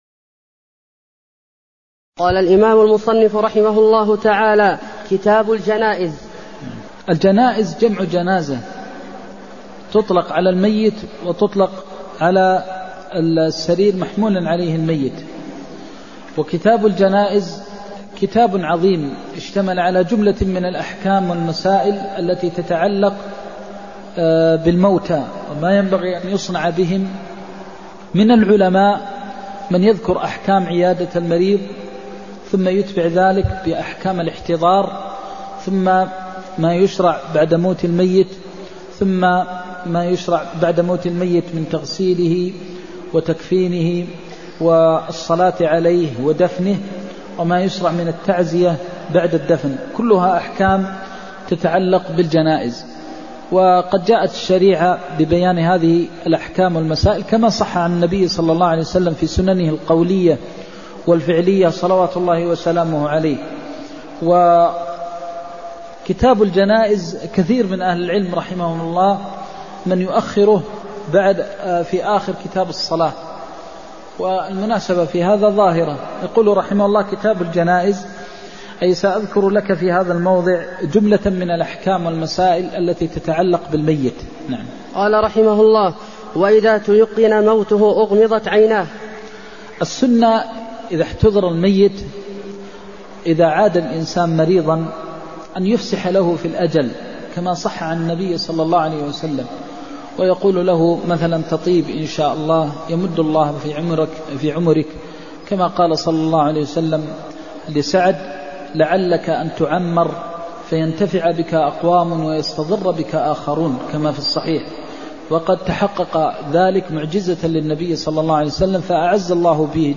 المكان: المسجد النبوي الشيخ: فضيلة الشيخ د. محمد بن محمد المختار فضيلة الشيخ د. محمد بن محمد المختار كتاب الجنائز (01)